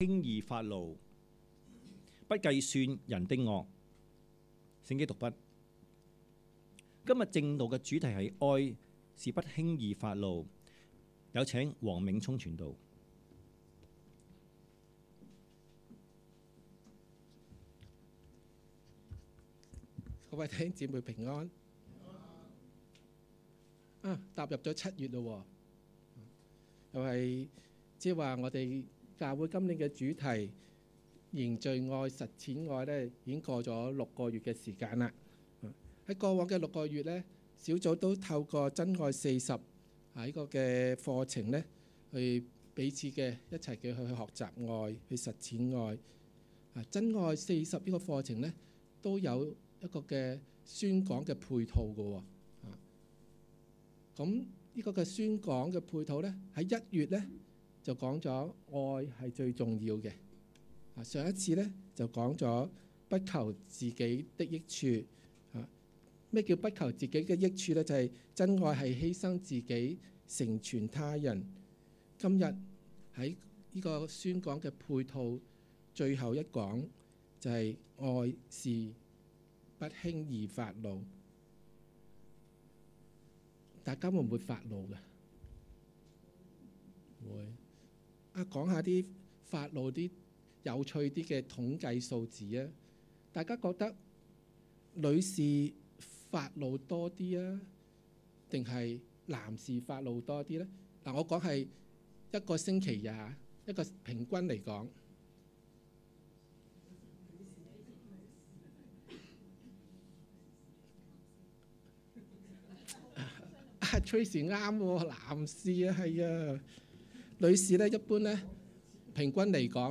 2016年7月16日及17日崇拜